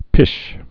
(pĭsh)